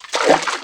HauntedBloodlines/STEPS Water, Walk 02.wav at main
STEPS Water, Walk 02.wav